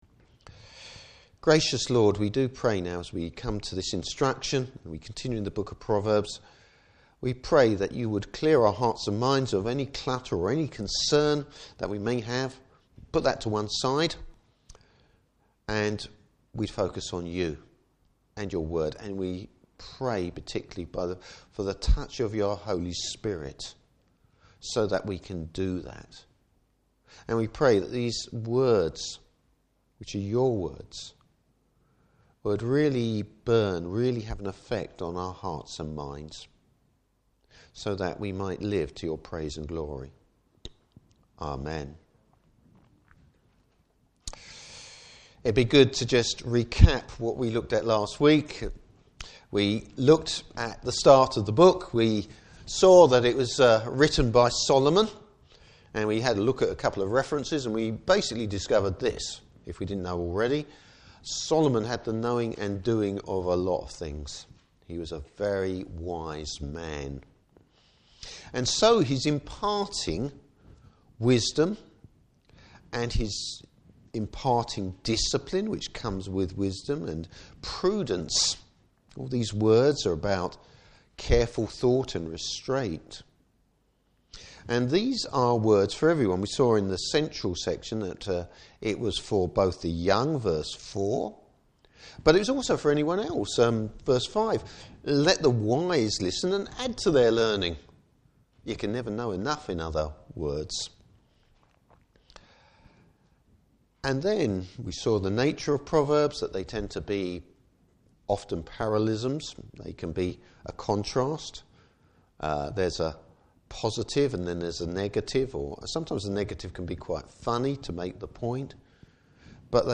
Service Type: Morning Service The wisdom of avoiding temptation.